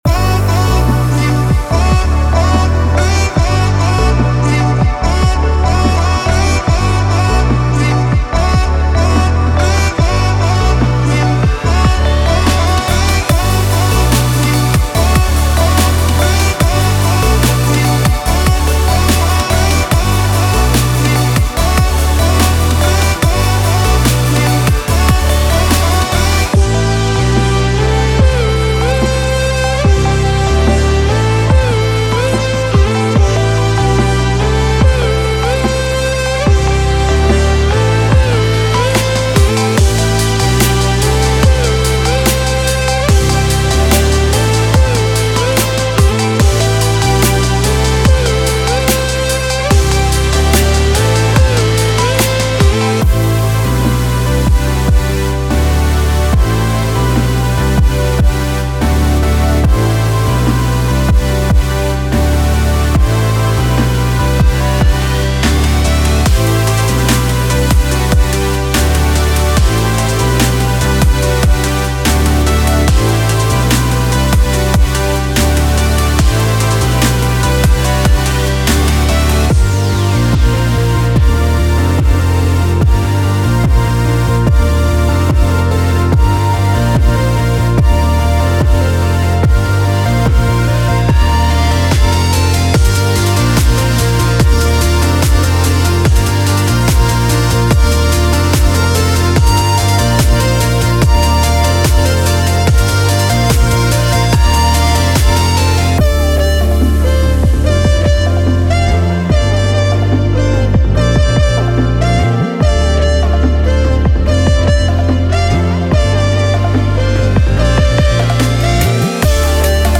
electrifying fusion of rock and electronic sounds